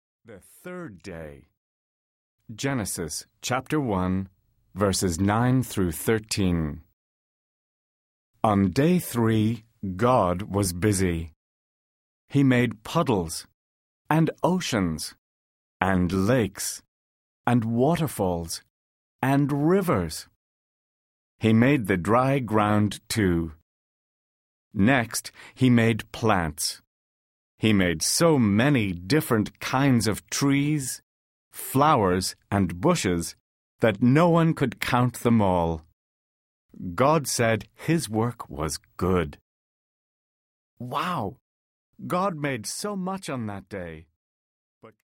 The Read and Share Bible Audiobook
Narrator
3.1 Hrs. – Unabridged